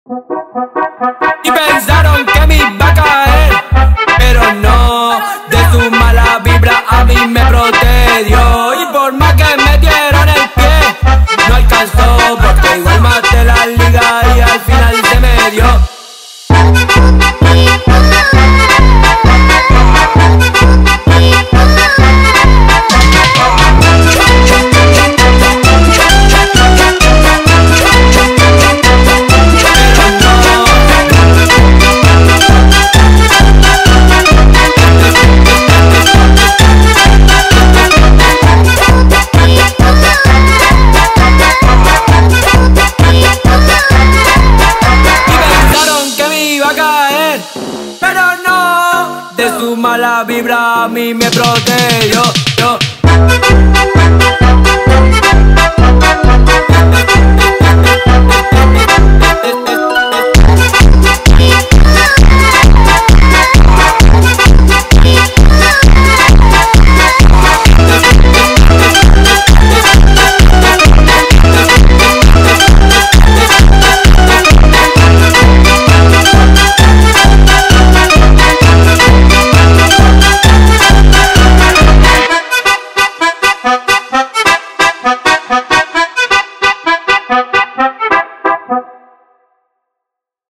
Скачать музыку / Музон / Speed Up